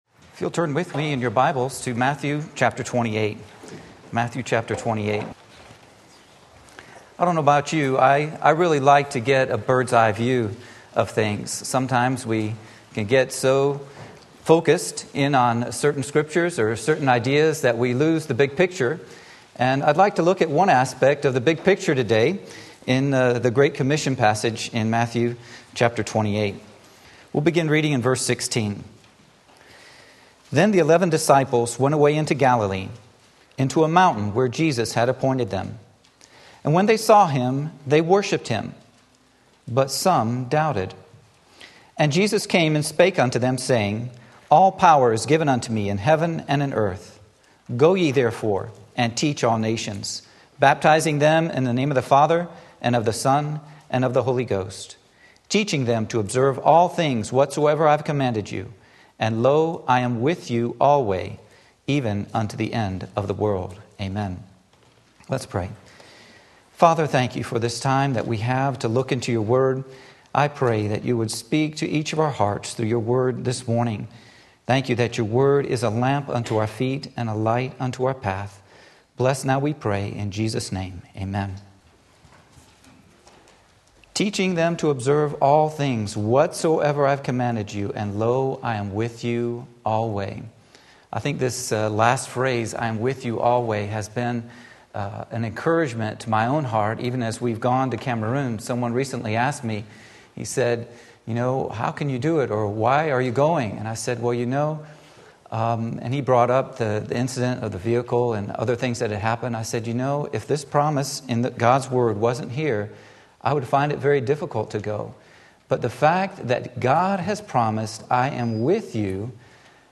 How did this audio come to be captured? I Am with You Always Matthew 28:16-20 Sunday Morning Service